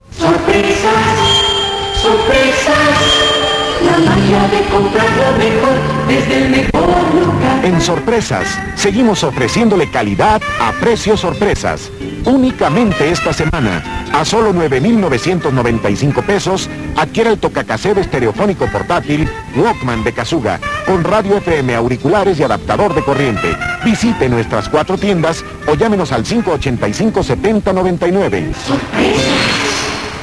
Toca Cassete Estereofónico Portatíl - Comercial 1982
En la radio de la ciudad de Mexico la tienda SORPRESAS anunciaba en 1982 el Walkman de Kasuga por sólo $9,995.00 pesos, hay que aclarar que este precio es del antiguo peso mexicano lo que vendría siendo actualmente $9.99 pesos.